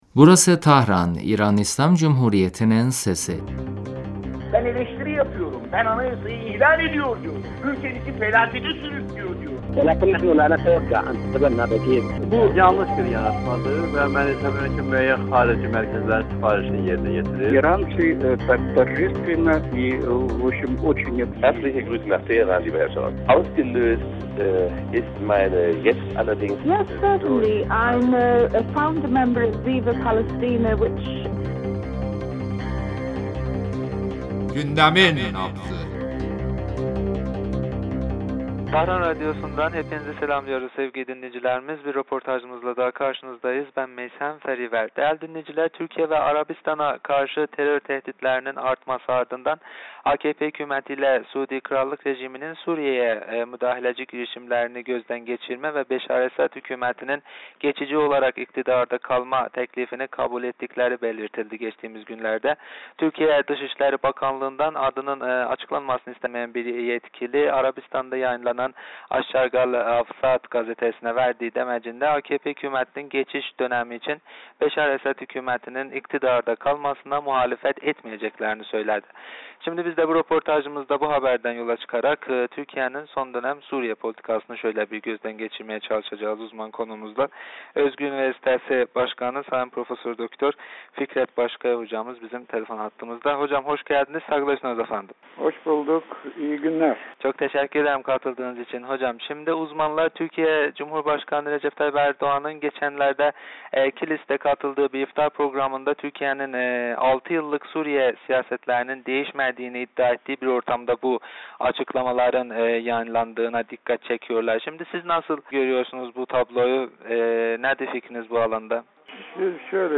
röportajımızda Türkiye'nin Suriye politikası hakkında konuştuk.